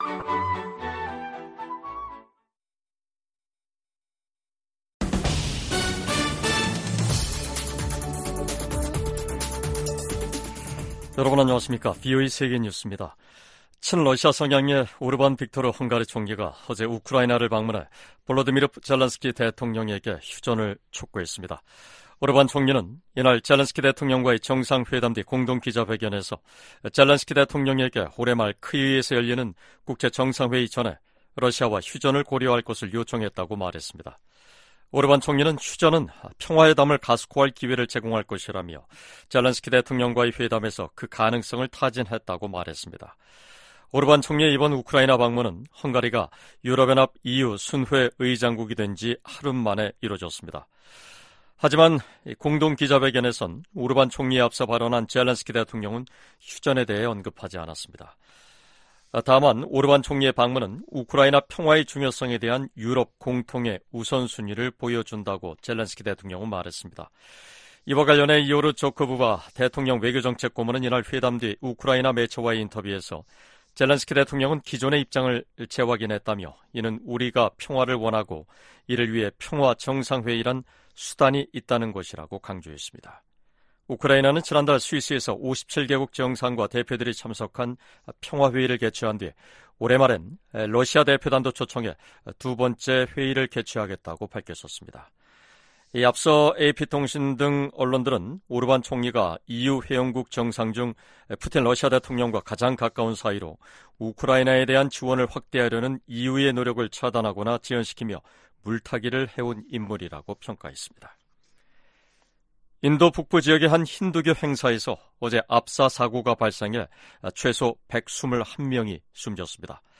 VOA 한국어 간판 뉴스 프로그램 '뉴스 투데이', 2024년 7월 3일 2부 방송입니다. 미국 국방부가 북한의 최근 탄도미사일 발사를 비판하며 계속 심각하게 받아들일 것이라고 밝혔습니다. 미국의 미사일 전문가들은 북한이 아직 초대형 탄두 미사일이나 다탄두 미사일 역량을 보유하지 못한 것으로 진단했습니다. 유엔 제재하에 있는 북한 선박이 중국 항구에 입항했습니다.